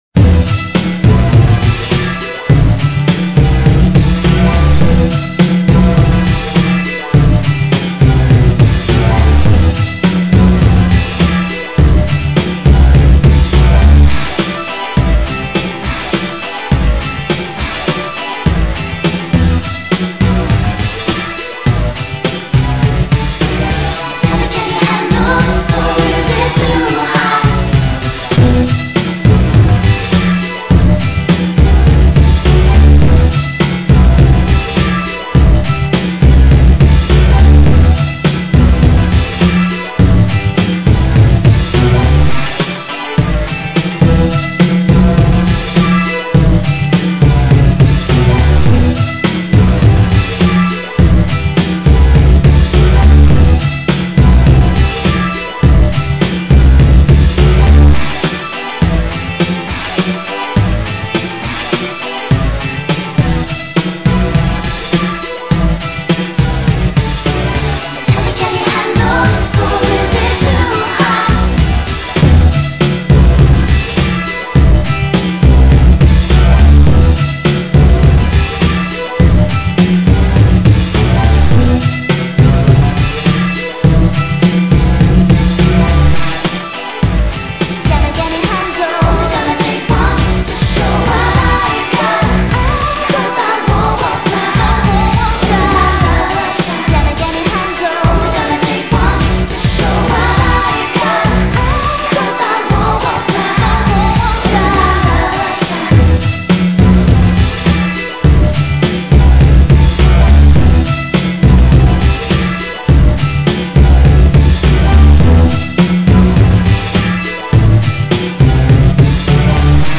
.MP3    (MPEG 2.5 layer 3, 24KB per second, 11,025 Hz, Mono)
Club
Tip: Turn your volume down before playing this one.